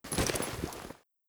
looting_9.ogg